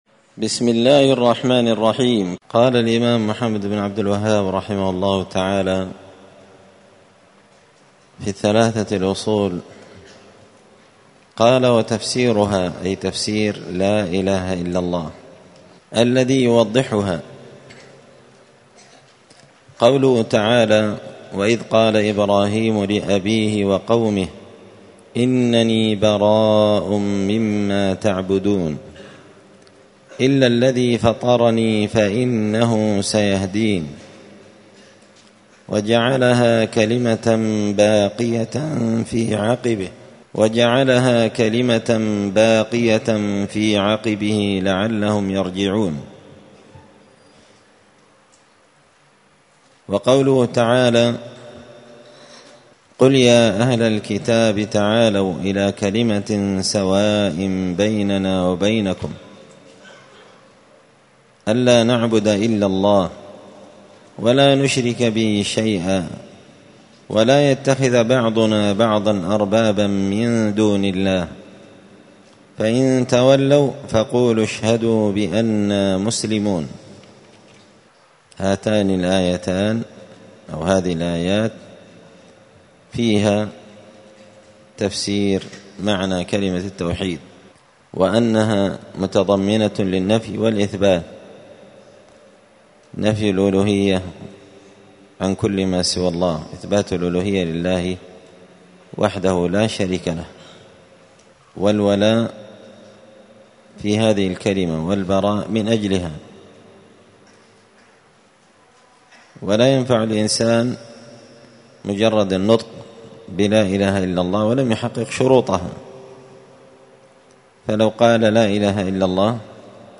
مسجد الفرقان قشن_المهرة_اليمن
22الدرس-الثاني-والعشرون-من-كتاب-حاشية-الأصول-الثلاثة-لابن-قاسم-الحنبلي.mp3